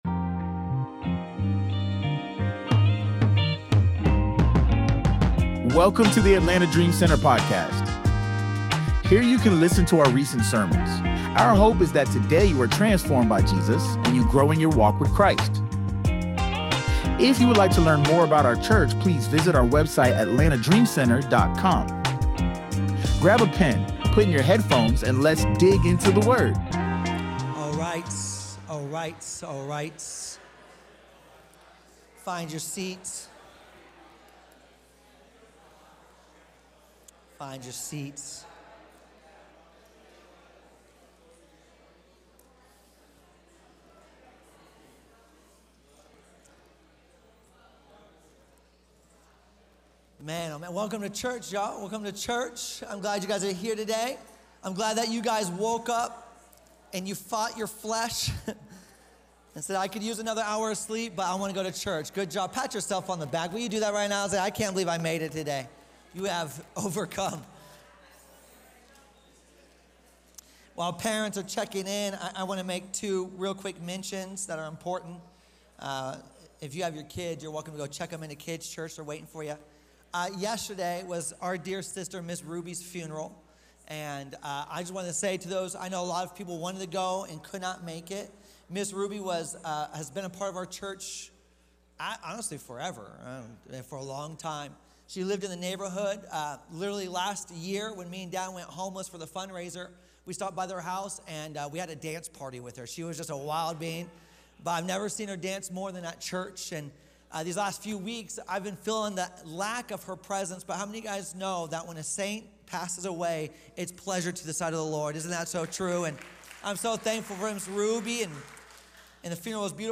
In this message, we begin a journey through the book of Romans by looking at Paul’s opening words and the posture of a true believer before God.